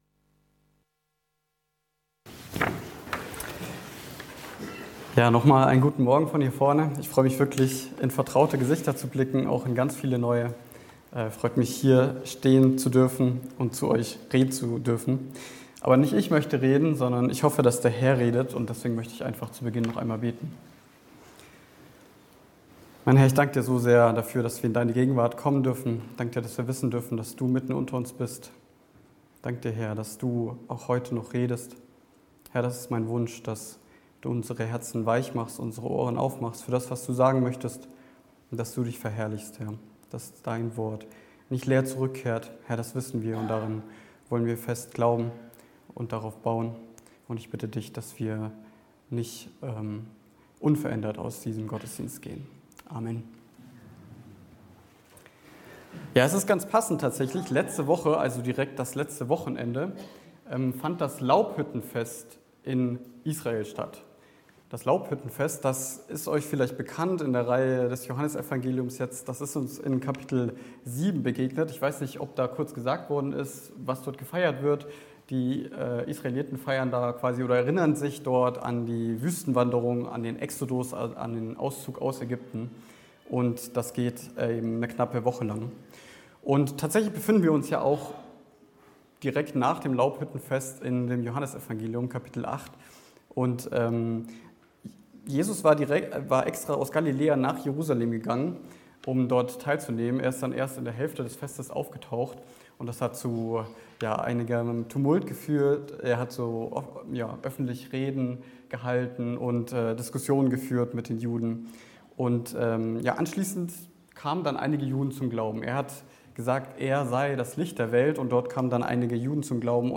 Die Punkte der Predigt waren: Weil er Gott gehorsam ist (Verse 48-50) Weil er voller Gnade ist (Verse 51-55) Weil er Gott selbst ist (Verse 56-59) HauskreisLeitfaden Aufnahme (MP3) 37 MB PDF 933 kB Zurück Das Wort der Wahrheit Weiter Die Heilung eines Blindgeborenen